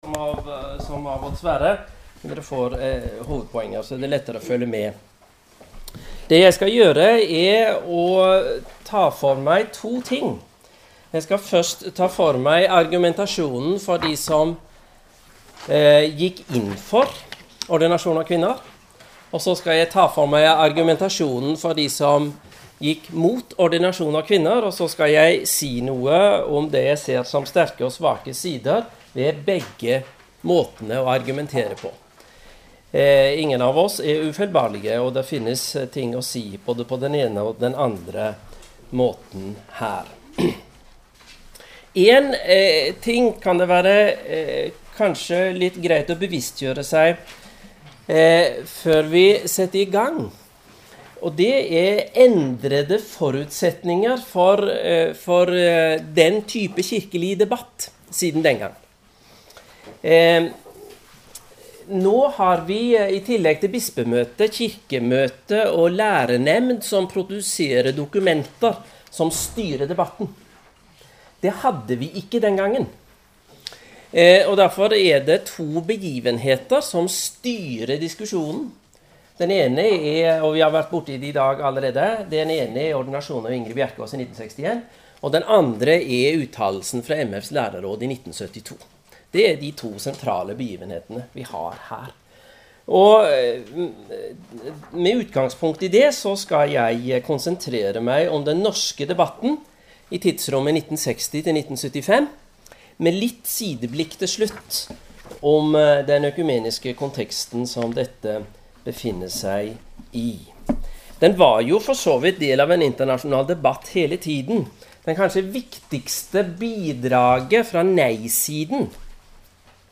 FBB-seminar 10. september 2011 (mp3).